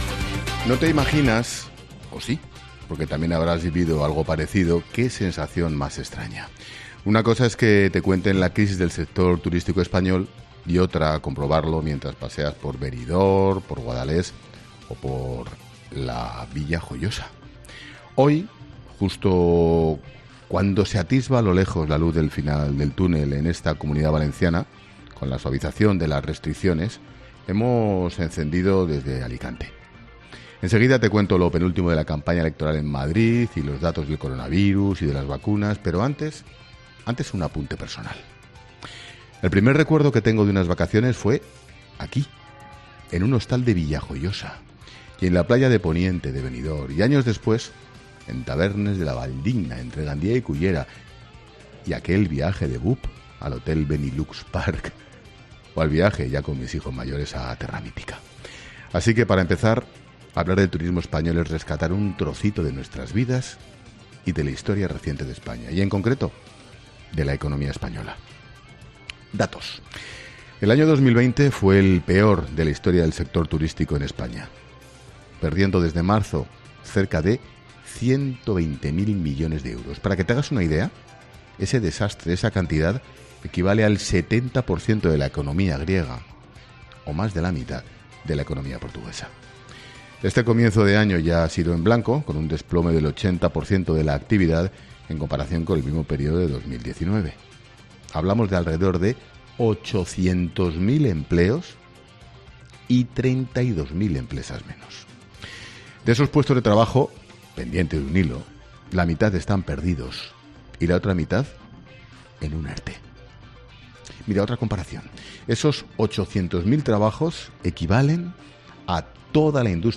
Monólogo de Expósito
El director de 'La Linterna', Ángel Expósito, reflexiona en su monólogo sobre las claves de actualidad de este lunes 26 de abril
Hoy, justo cuando se atisba a lo lejos la luz del final del túnel en esta Comunidad Valenciana con la suavización de las restricciones, hemos encendido La Linterna desde Alicante. Enseguida te cuento lo penúltimo de la campaña electoral en Madrid y los datos del coronavirus y de las vacunas.